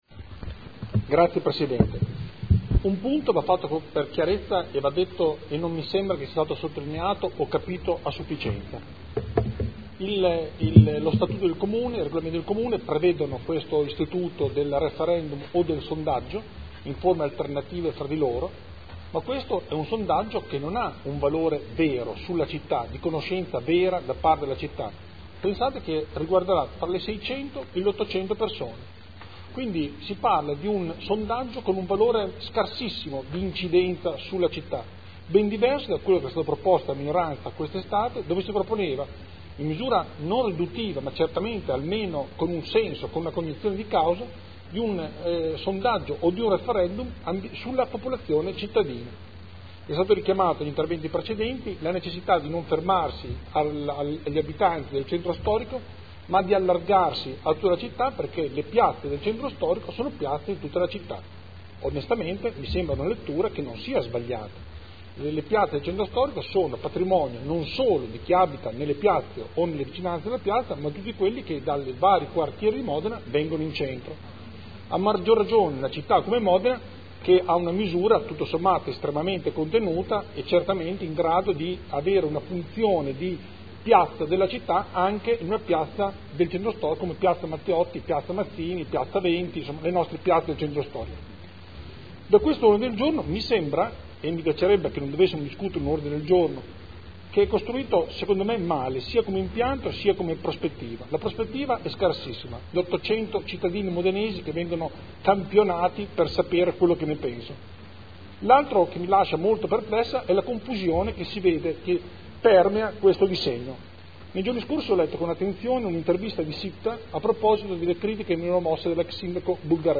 Andrea Galli — Sito Audio Consiglio Comunale
Seduta del 05/09/2011. Dibattito su Consultazione popolare ai sensi dell’art. 8 del Regolamento degli Istituti di Partecipazione del Comune di Modena sul progetto di ristrutturazione di Piazza Matteotti mediante sondaggio (Conferenza Capigruppo del 5 settembre 2011)